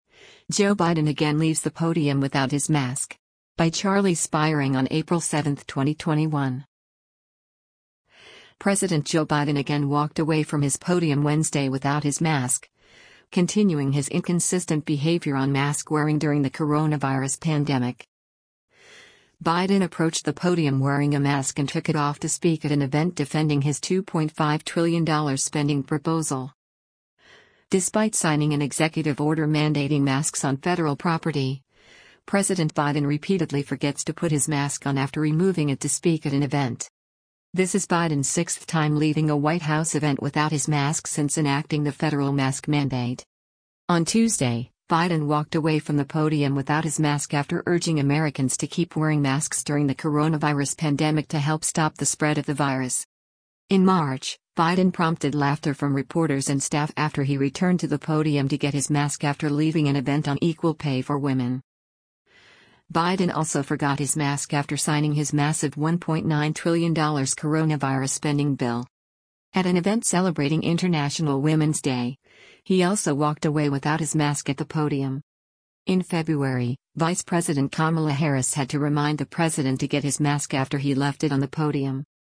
US President Joe Biden speaks about infrastructure investment from the Eisenhower Executiv
Biden approached the podium wearing a mask and took it off to speak at an event defending his $2.5 trillion spending proposal.